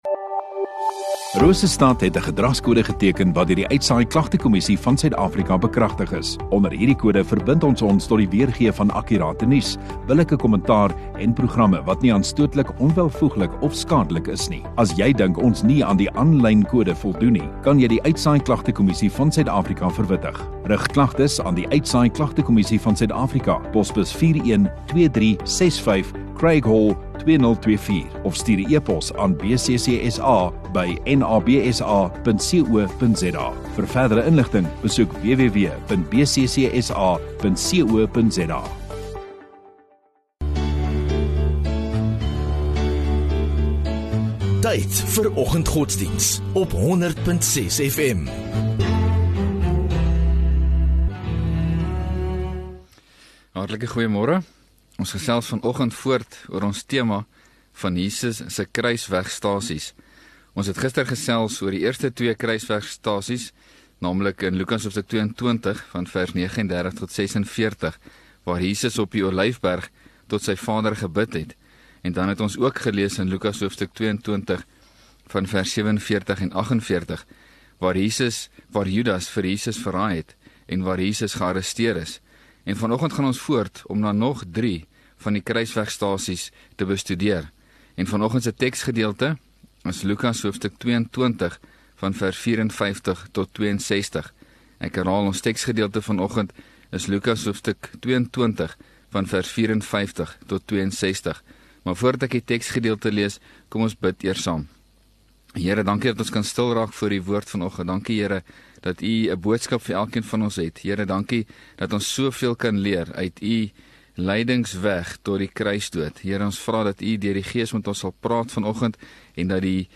2 Apr Woensdag Oggenddiens